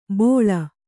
♪ bōḷa